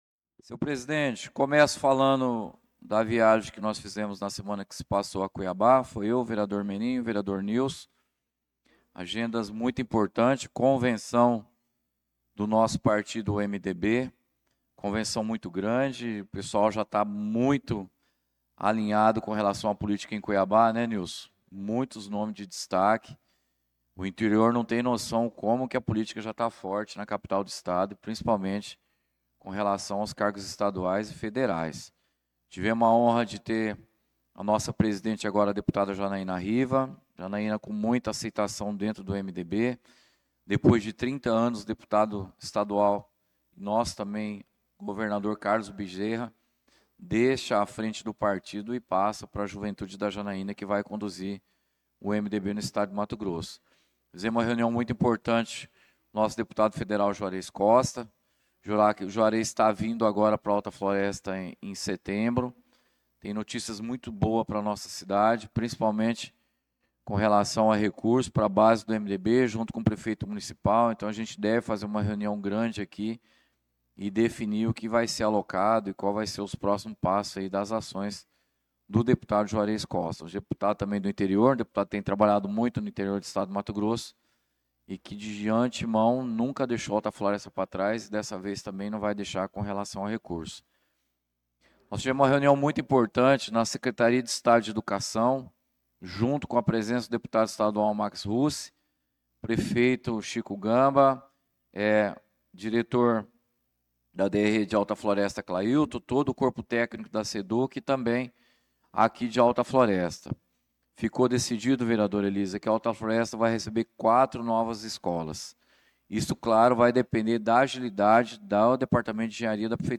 Pronunciamento do vereador Claudinei de Jesus na Sessão Ordinária do dia 25/08/2025.